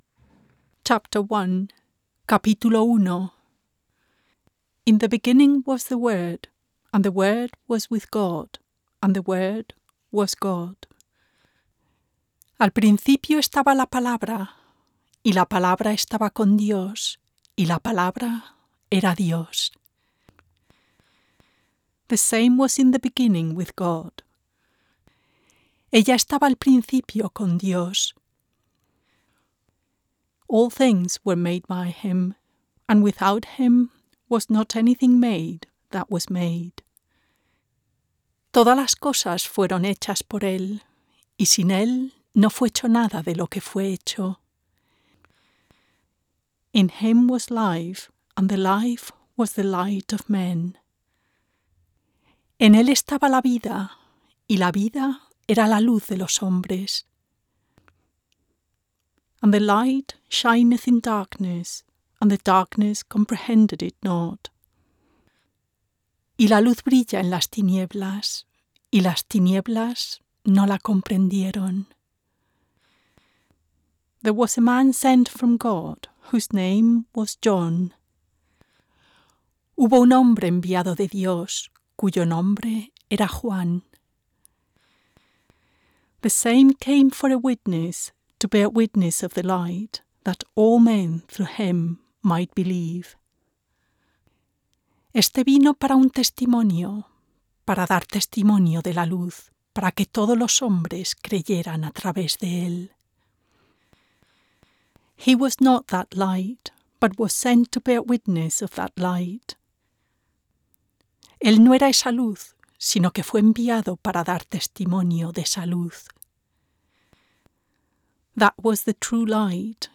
Audiobook
Verse-by-verse bilingual recording of The Gospel of John - El Evangelio según San Juan.